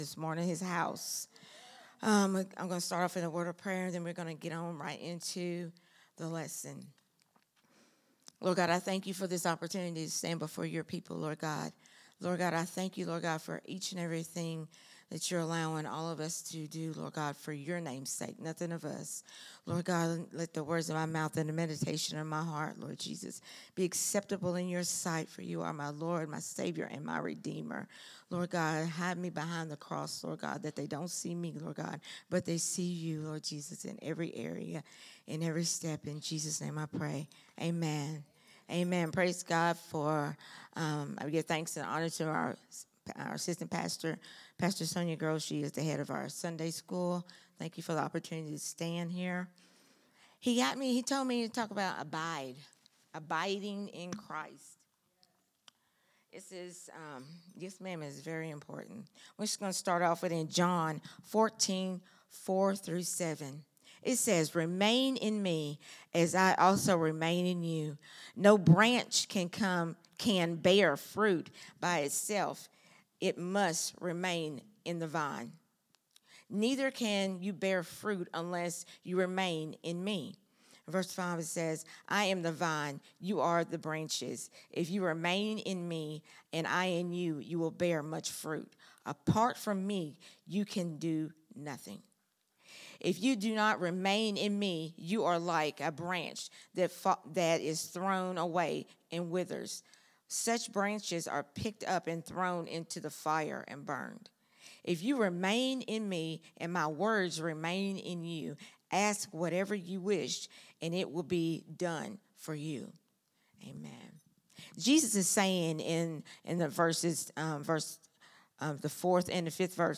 recorded at Growth Temple Ministries on Sunday, November 9, 2025.